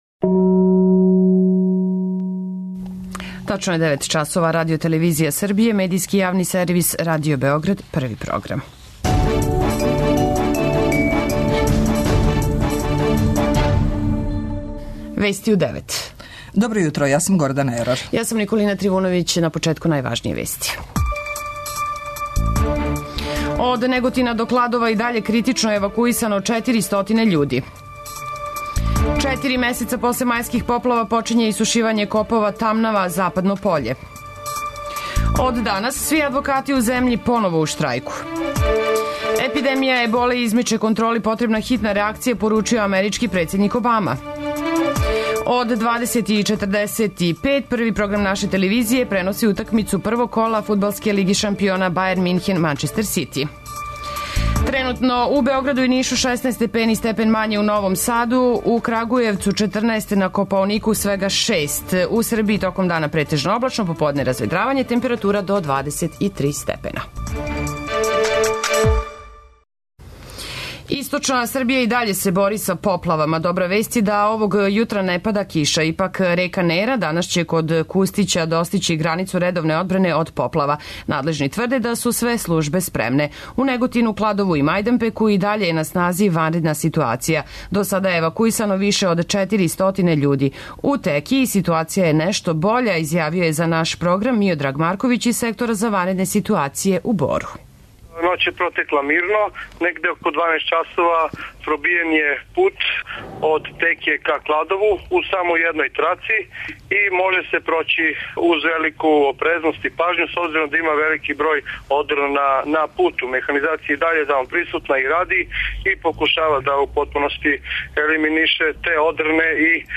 преузми : 9.63 MB Вести у 9 Autor: разни аутори Преглед најважнијиx информација из земље из света.